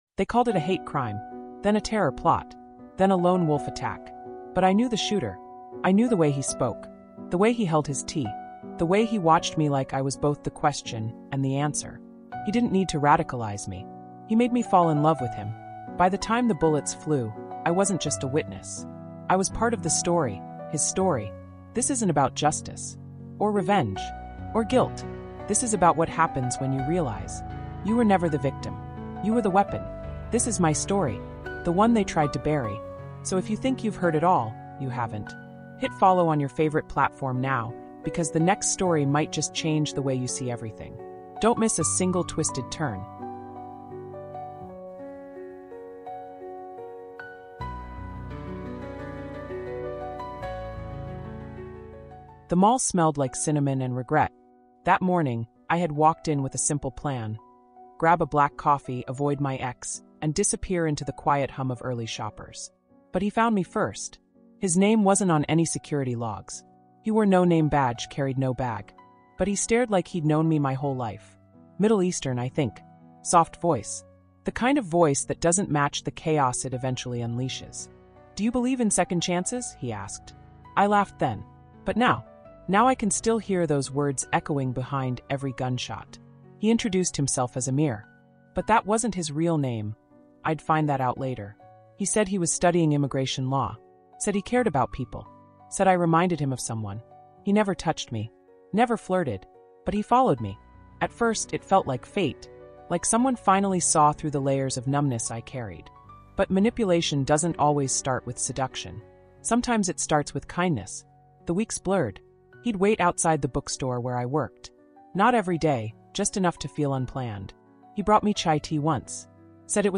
In a world of staged narratives and hidden agendas, one woman unravels the terrifying truth behind a mass shooting at the Boulder Mall—a truth far more intimate and manipulated than the public will ever know. Told in immersive first-person narration, MANIPULATION: Boulder Mall Attack — The False Flag Lover is a chilling psychological thriller inspired by real events.